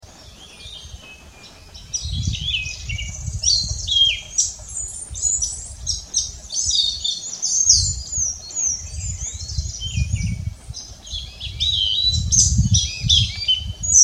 Sabía-ferreiro (Turdus nigriceps)
Nome em Inglês: Andean Slaty Thrush
Localidade ou área protegida: Parque Nacional Aconquija
Condição: Selvagem
Certeza: Gravado Vocal